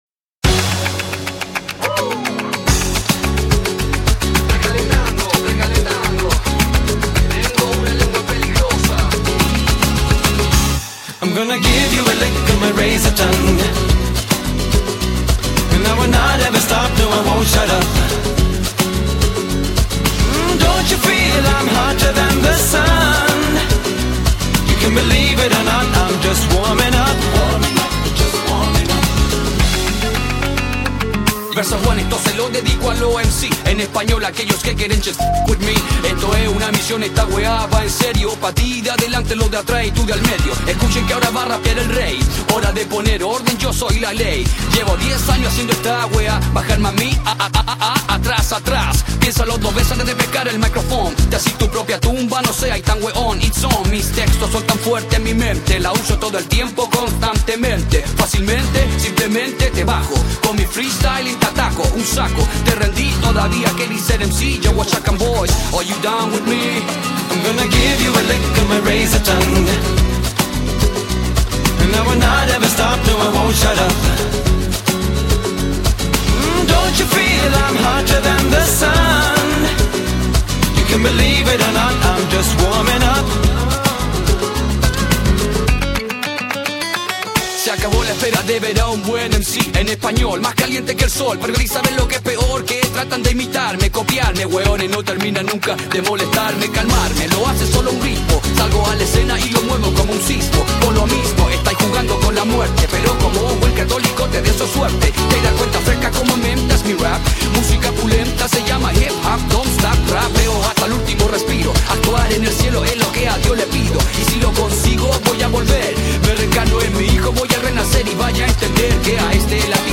latino_zazhigatel_naya_pesnya.mp3